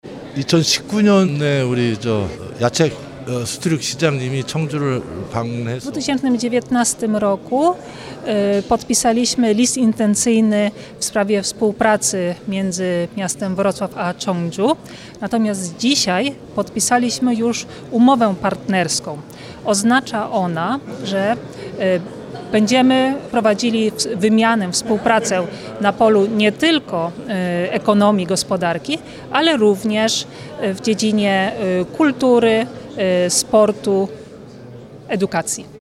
W Sali Wielkiej Starego Ratusza doszło do podpisania umowy partnerskiej między stolicą Dolnego Śląska a południowokoreańskim Cheongju.